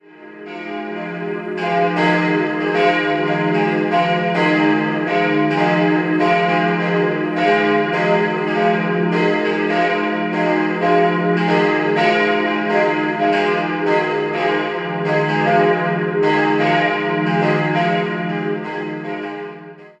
3-stimmiges Gloria-Geläute: es'-f'-as' Die beiden größeren Glocken sind dem Heiligen Michael sowie den Apostelfürsten Petrus und Paulus geweiht und wurden 1953 vom Bochumer Verein für Gussstahlfabrikation gegossen. Vom alten Oberascher-Geläut aus der Erbauungszeit der Kirche ist noch die kleine Glocke aus dem Jahr 1937 vorhanden.